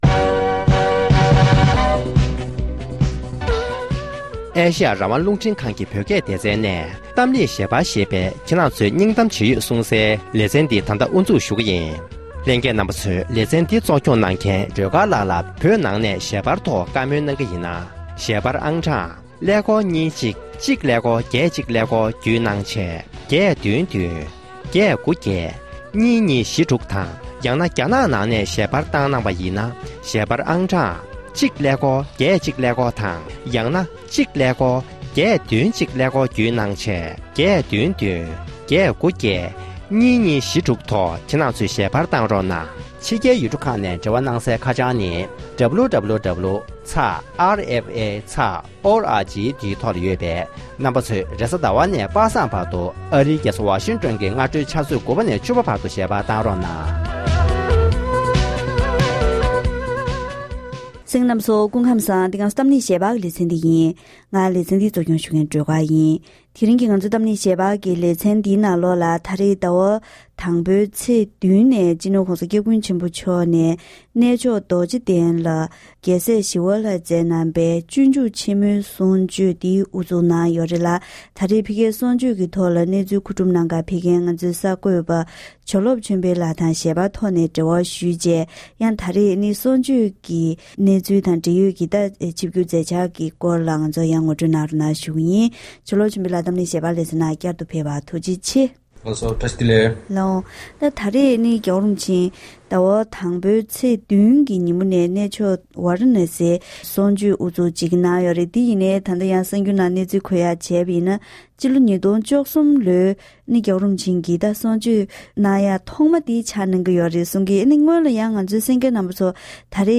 གཏམ་གླེང་ཞལ་པར་ལེ་ཚན